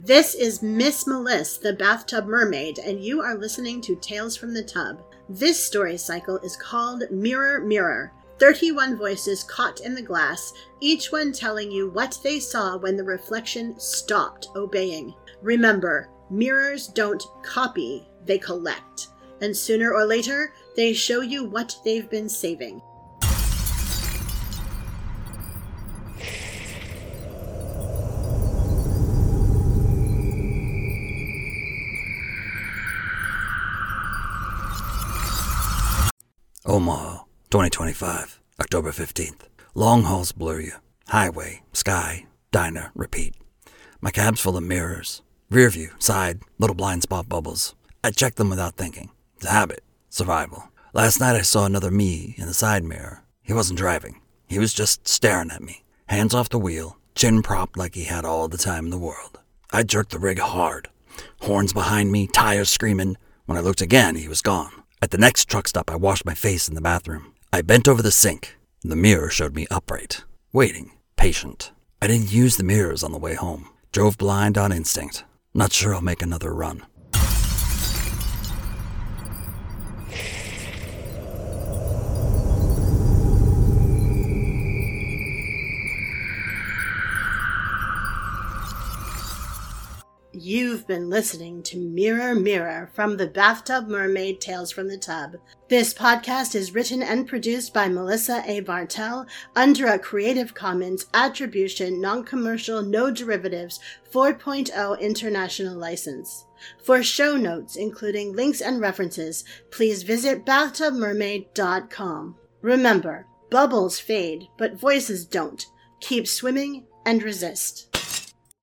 🎧 Mirror Mirror is a 31-day audio fiction cycle running through October — one haunting story every day from the world beyond the glass.